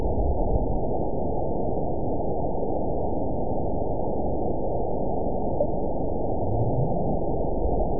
event 920388 date 03/22/24 time 22:35:54 GMT (1 year, 1 month ago) score 9.32 location TSS-AB02 detected by nrw target species NRW annotations +NRW Spectrogram: Frequency (kHz) vs. Time (s) audio not available .wav